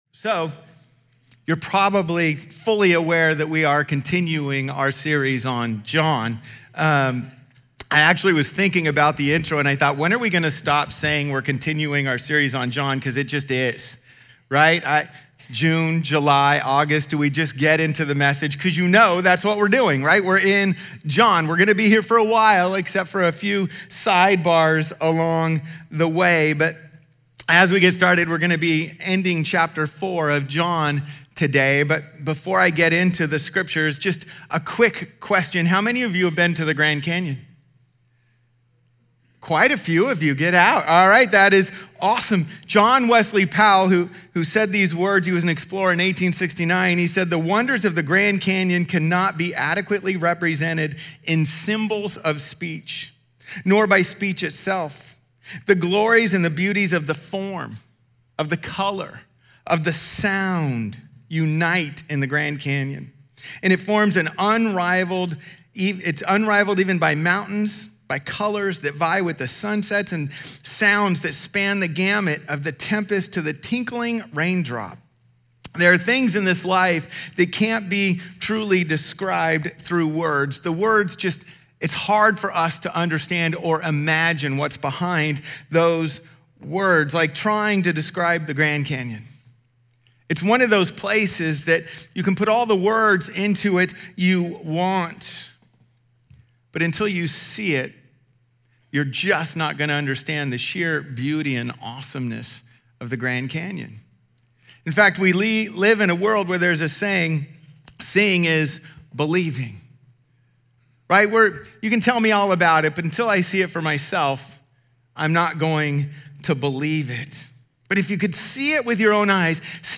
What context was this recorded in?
Faith Beyond Miracles | John 4:43-54 - Sonrise Church, Santee